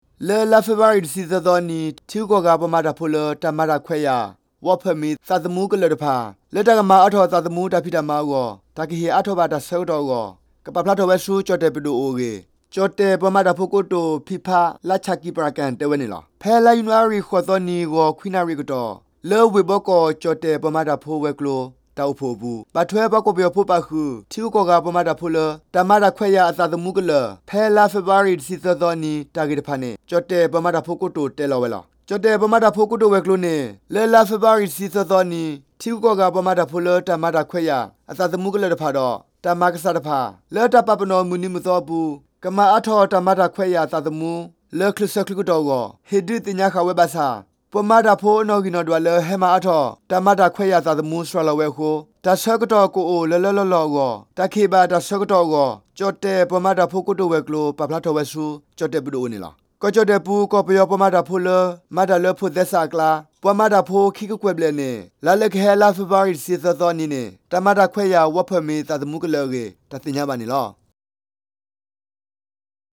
Radio တၢ်ကစီၣ် ခီကီၢ်ပှၤမၤတၢ်ဖိဂ့ၢ်၀ီ တၢ်ကစီၣ်